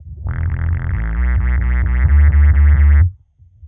Index of /musicradar/dub-designer-samples/130bpm/Bass
DD_JBassFX_130E.wav